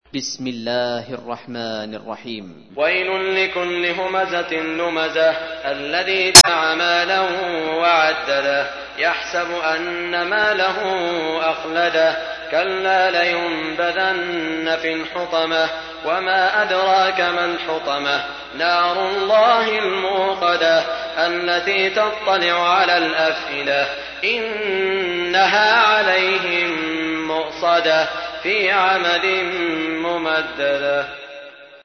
تحميل : 104. سورة الهمزة / القارئ سعود الشريم / القرآن الكريم / موقع يا حسين